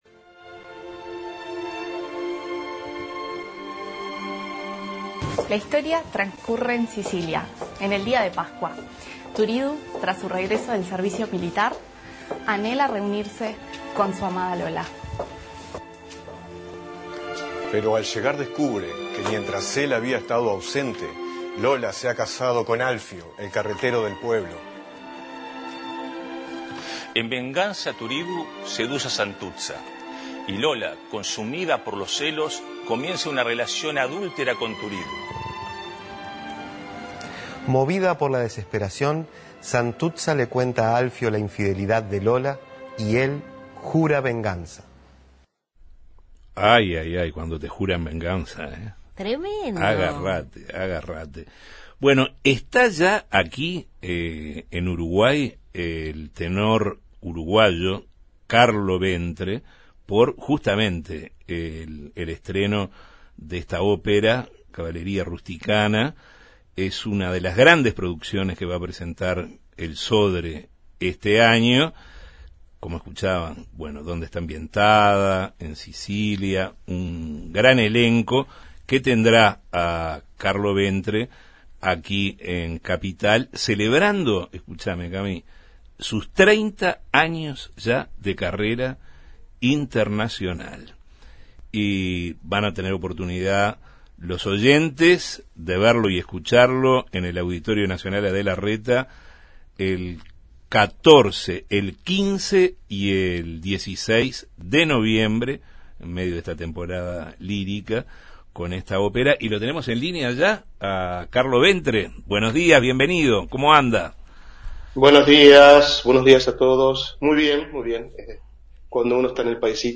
En Justos y pecadores entrevistamos al tenor uruguayo Carlo Ventre